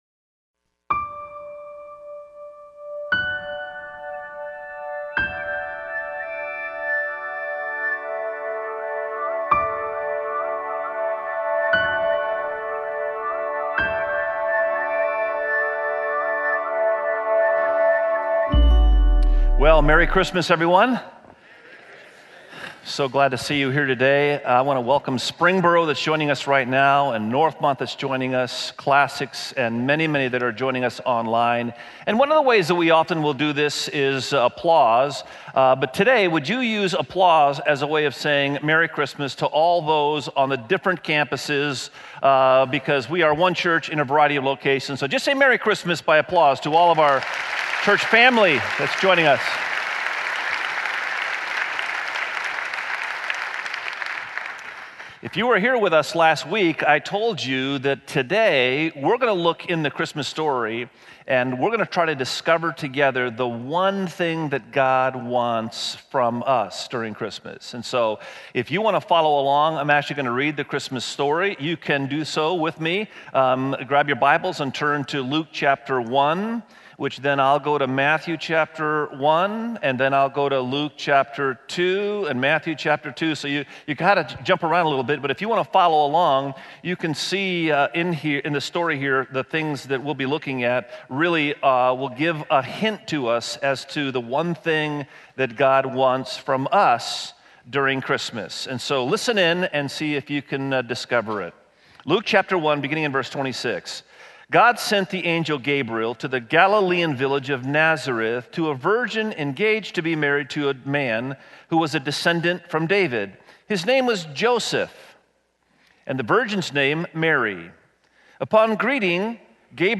Check out Christmas, a sermon series at Fairhaven Church.
Sermon Series